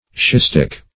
schistic - definition of schistic - synonyms, pronunciation, spelling from Free Dictionary Search Result for " schistic" : The Collaborative International Dictionary of English v.0.48: Schistic \Schist"ic\, a. Schistose.
schistic.mp3